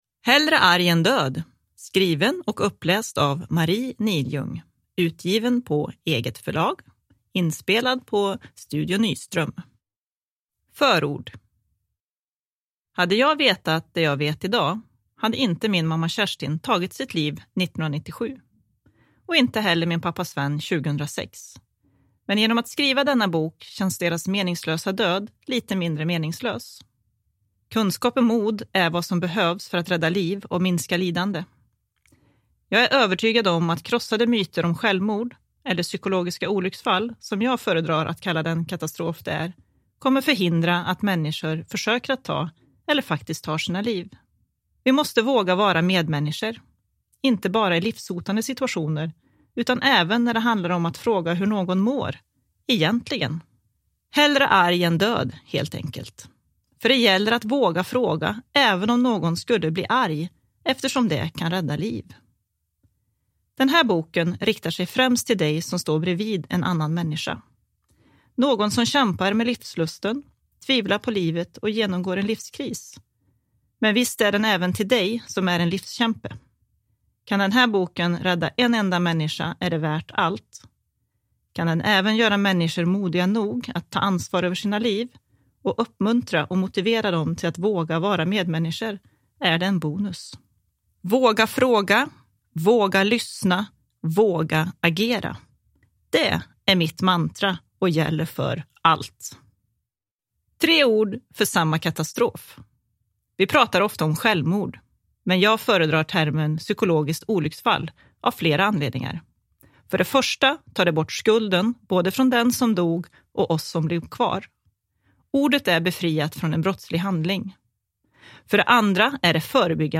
Hellre arg än död – Ljudbok – Laddas ner